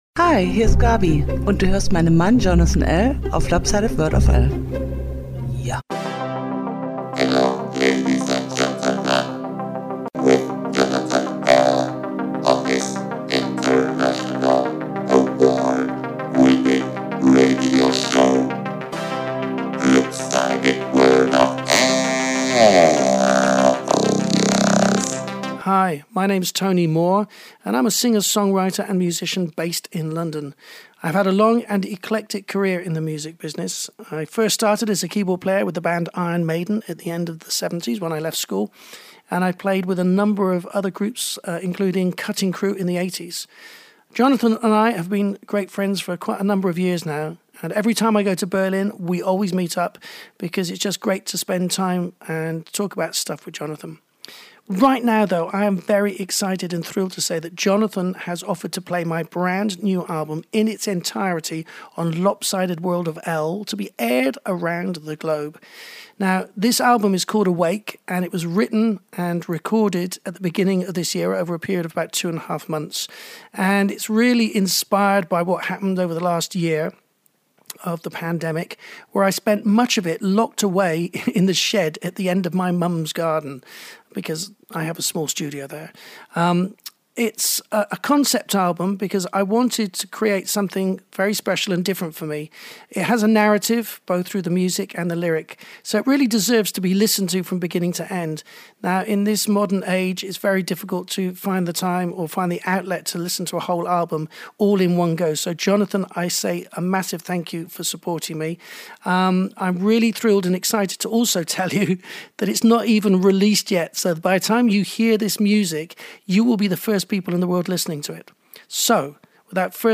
Drums
back and front announce